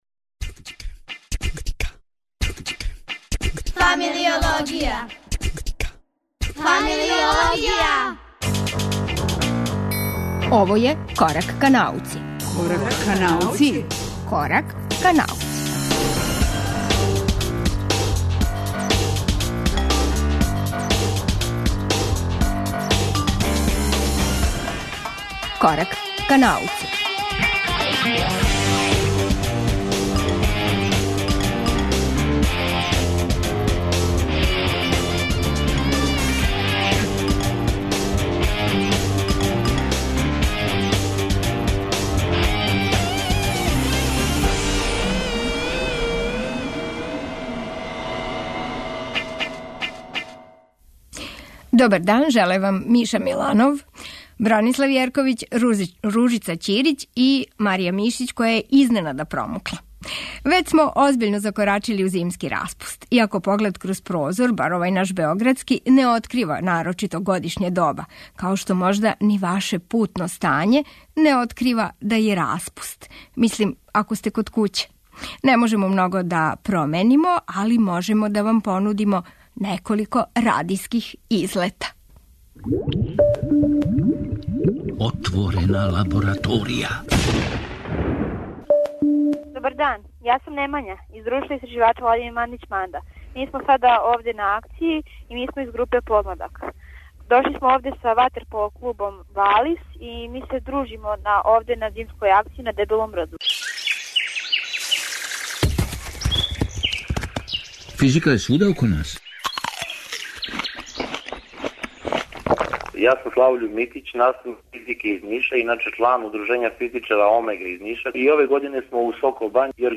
Емисију чине два телефонска укључења - са зимског кампа на Дебелом брду и Школе физике из Сокобање, WЕБ адресар - сајт јужноевропске астрономске опсерваторије у Чилеу и репортажа из Стопића пећине на Златибору.